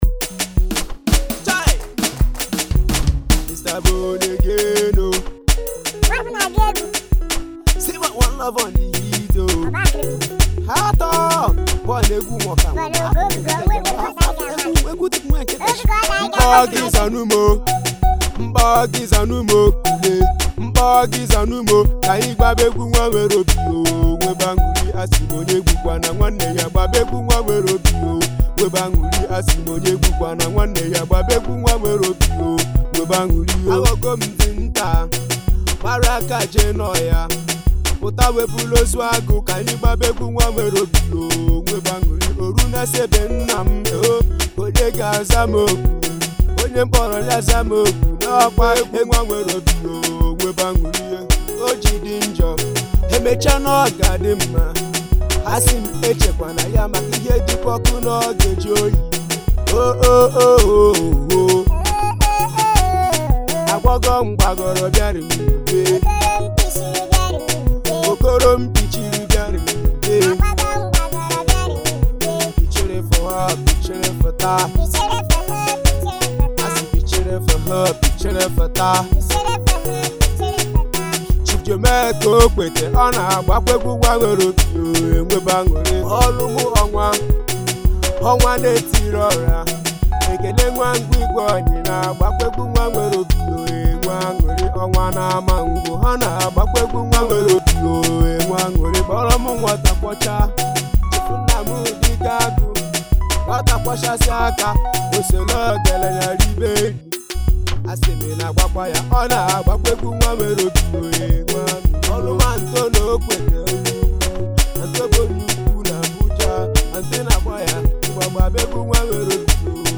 known for his magnetic presence and genre-bending style.